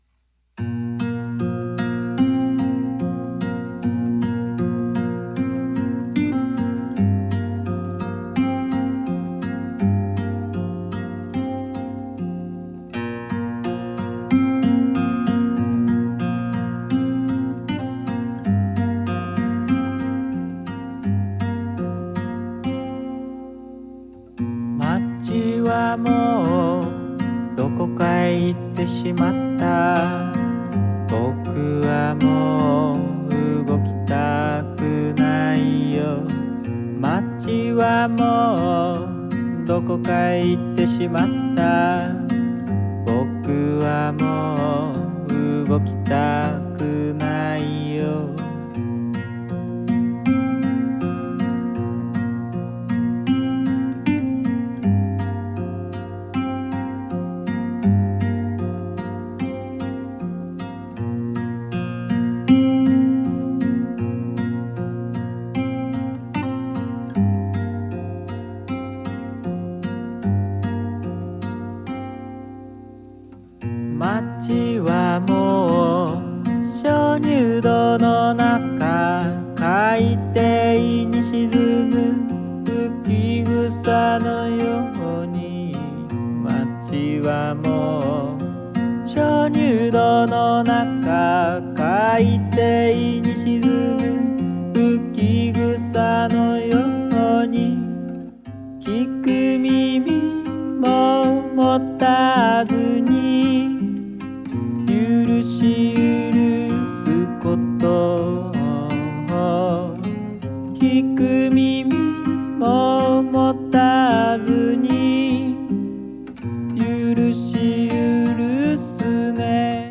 仄暗くもロマンチックな風情をまとったフォーキーな歌が、詩的な哀愁を帯びて響いてくる好内容！